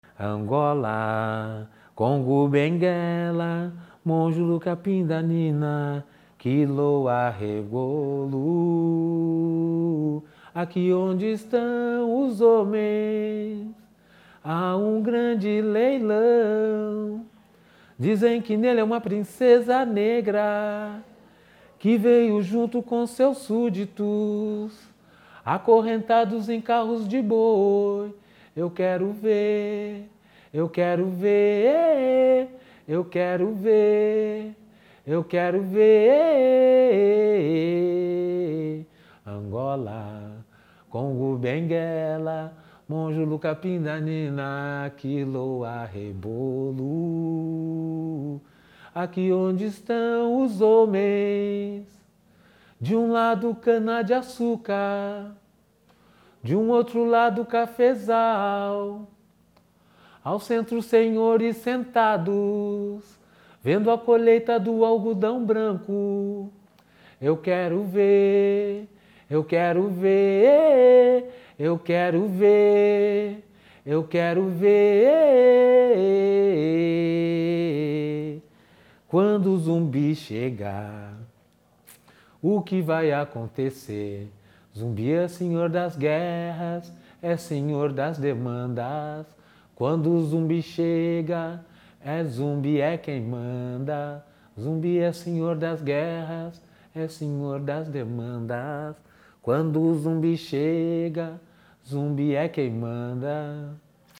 Gênero: Samba-Reggae